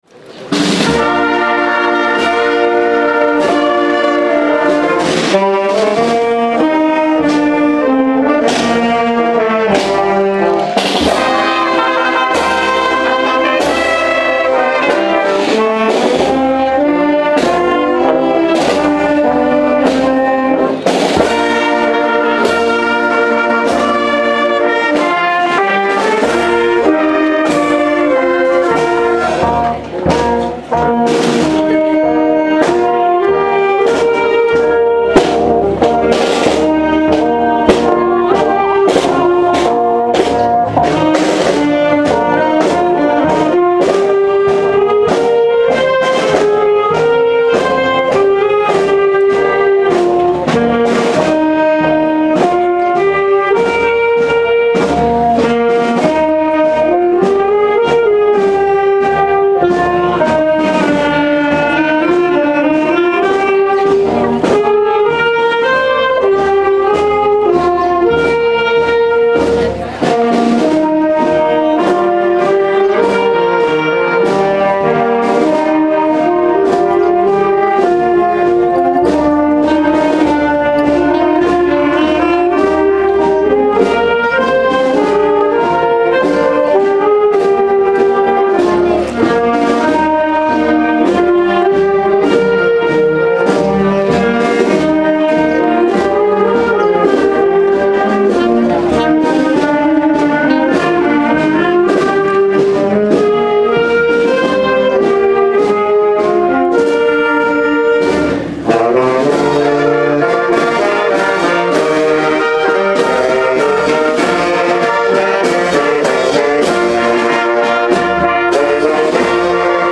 Procesión del "Lunes de los Frailes" 2014
La procesión del Lunes de los Frailes recorrió las calles de la localidad
Calles engalanadas con plantas y altares recibieron el pasado lunes, 23 de junio, el paso de la procesión del Lunes de los Frailes.
Participaron en ella numerosos niños que este año han tomado su Primera Comunión, que lucieron con sus trajes en este día, autoridades municipales, numerosos vecinos y la banda de la Agrupación Musical de Totana.